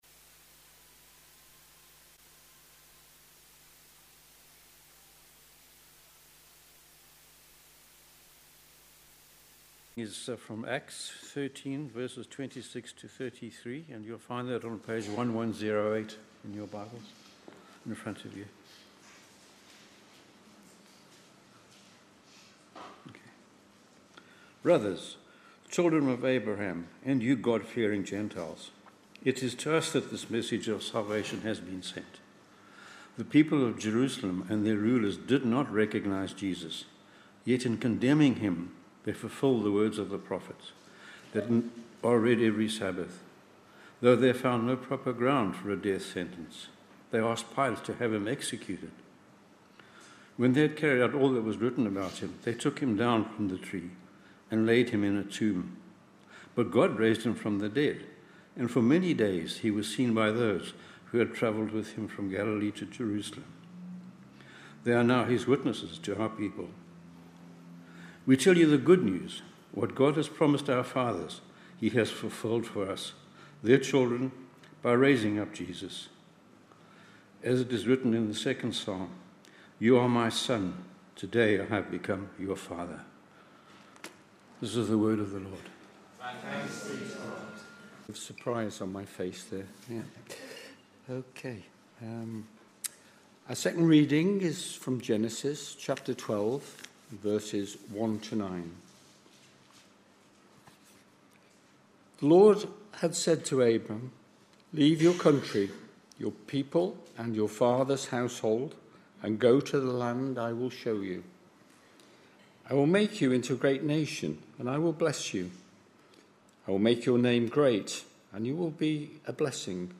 Readings-Sermon-on-3rd-August-2025.mp3